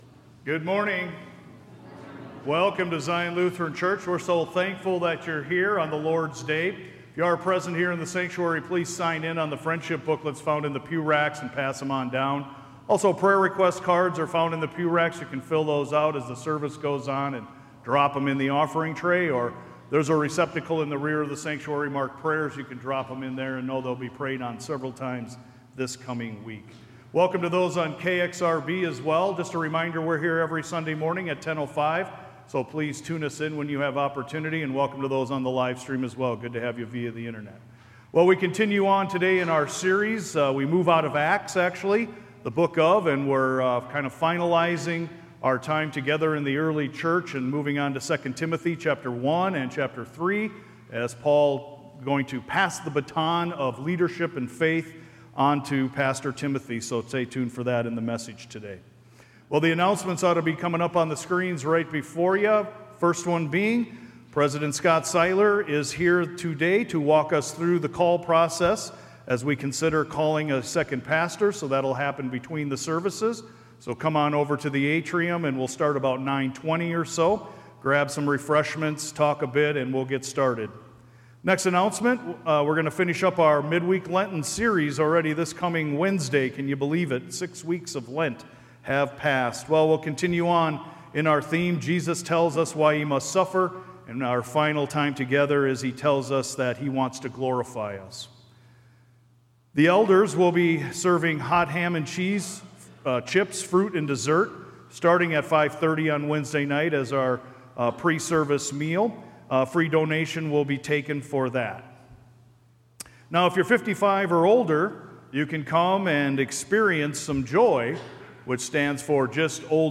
ZION-SERMON_APRIL_6_2025.mp3